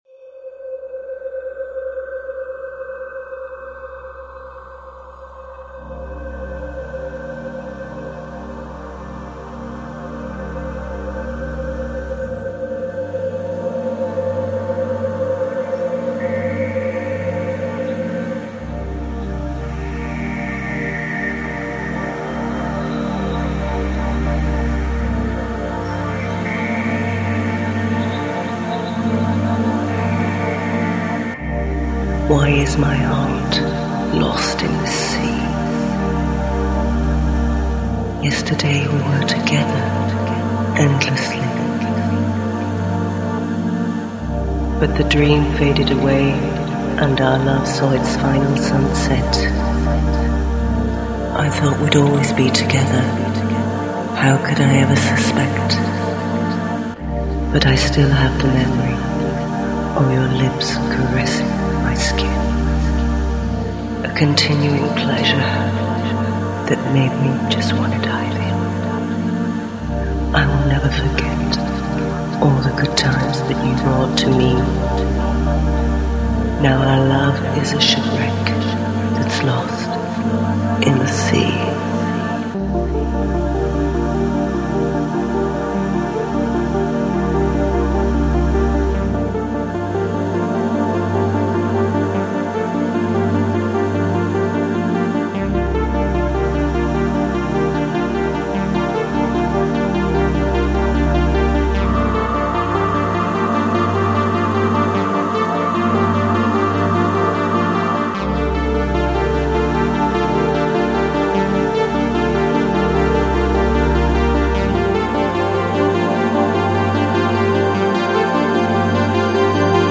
Electronic, Lounge, Chill Out, Downtempo Год издания